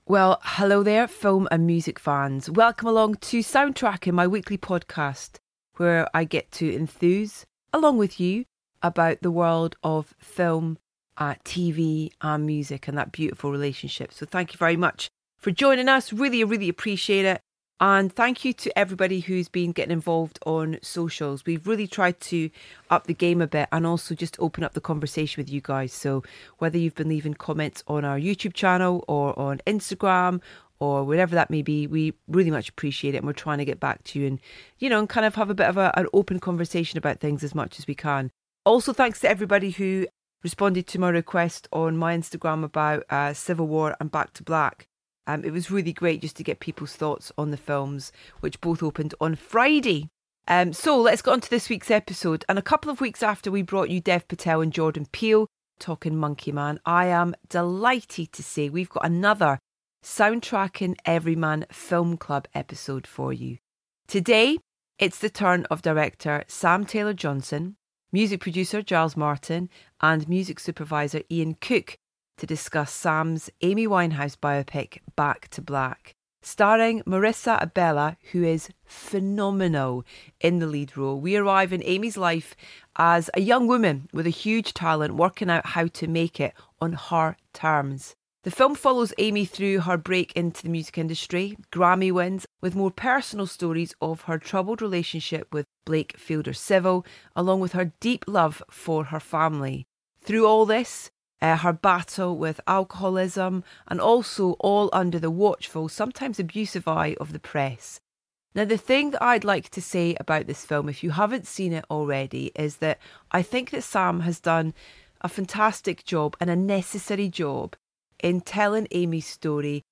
A couple of weeks after we.brought you Dev Patel and Jordan Peele talking Monkey Man, we're delighted to say we have another live Soundtracking Everyman Film Club for you.